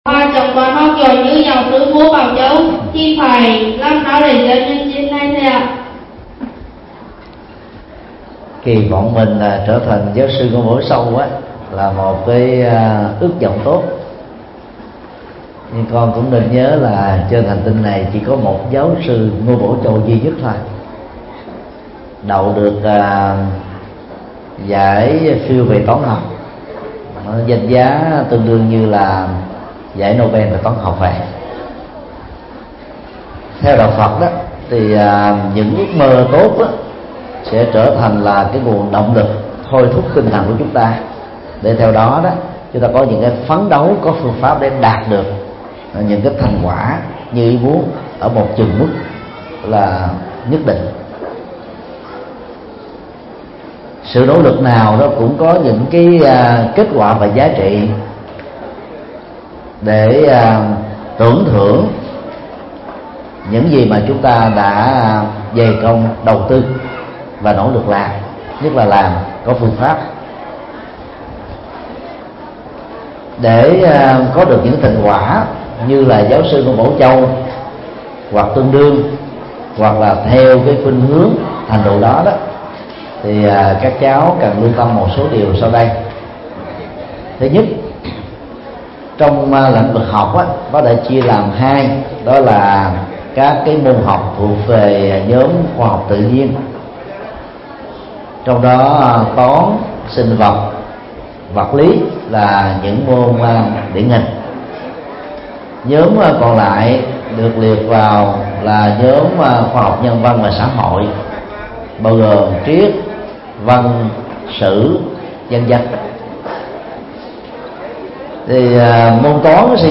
Vấn đáp: Phương pháp để học giỏi